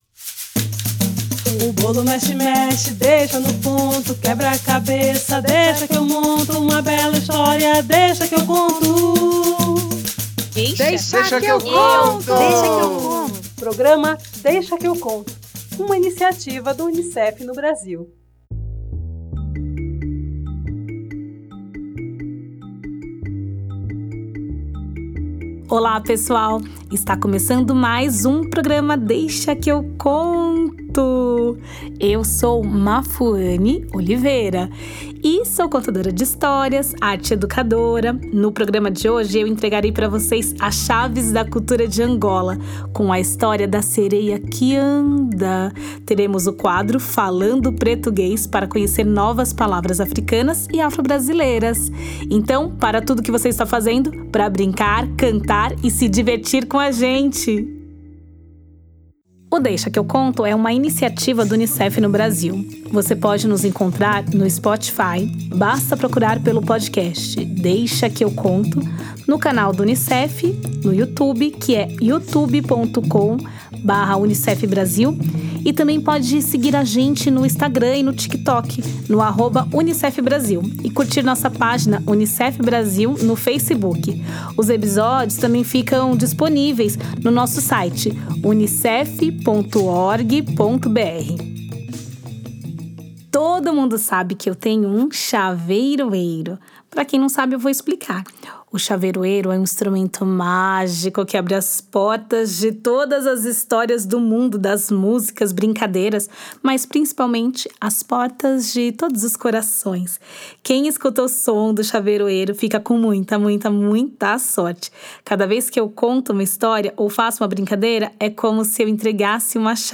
Conheceremos um pouco das línguas faladas em Angola e a diversão segue com músicas e brincadeiras com palavras afro-brasileiras no quadro falando “pretoguês”.